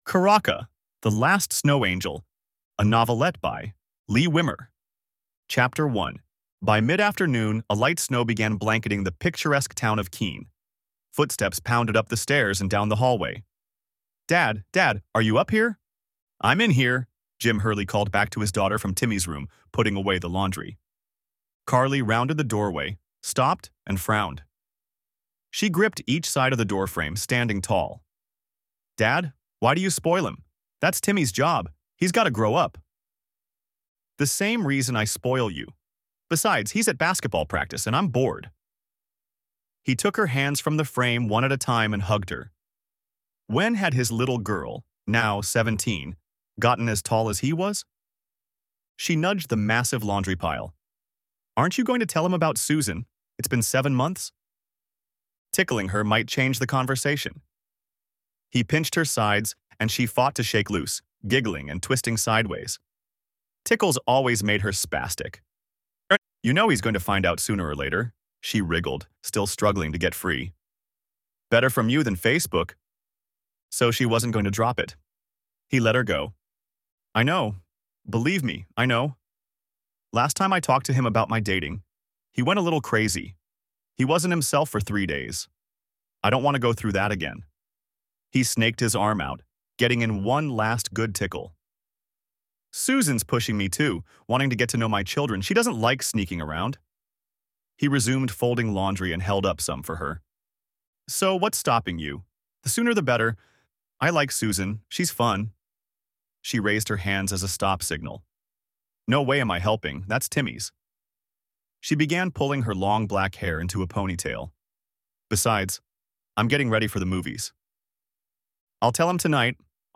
One Male narrator reads Charaka – The Last Snow Angel